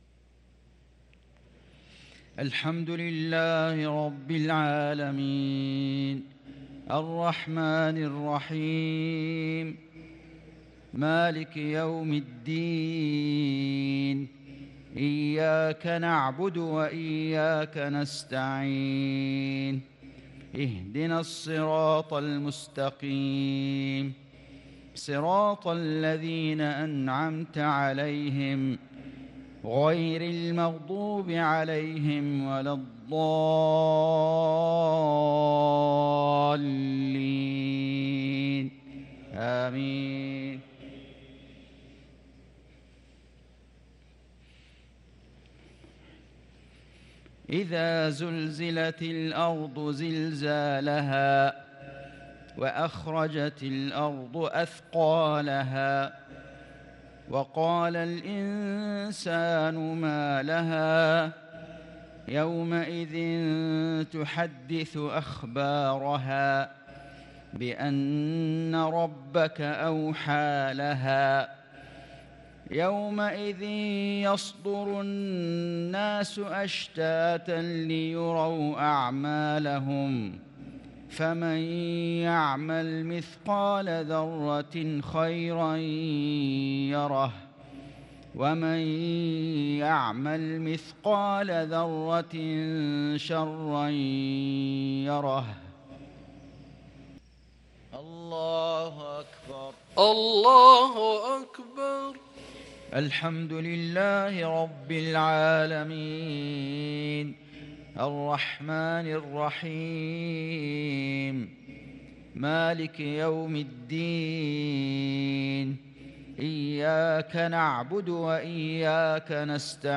صلاة المغرب للقارئ فيصل غزاوي 27 شعبان 1443 هـ
تِلَاوَات الْحَرَمَيْن .